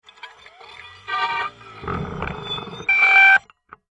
Звуки жесткого диска